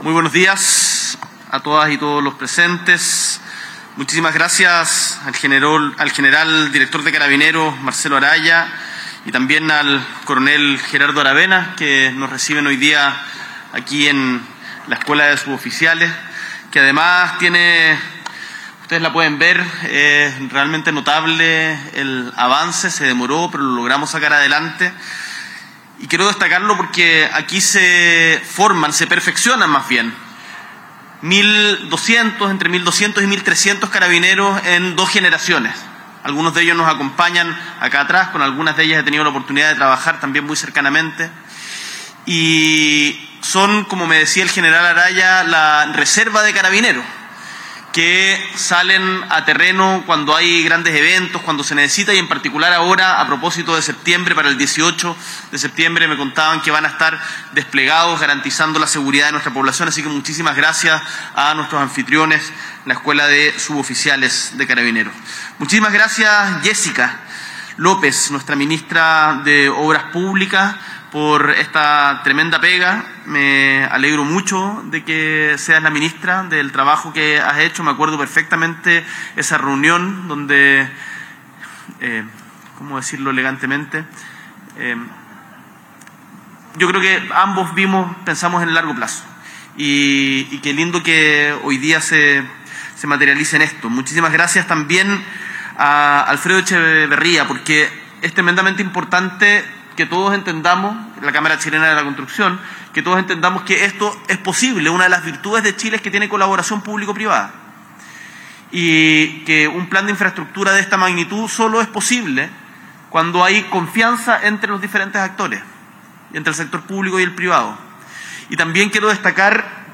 S.E. el Presidente de la República, Gabriel Boric Font, encabeza el lanzamiento del Plan Nacional de Infraestructura Pública 2025 - 2055
Discurso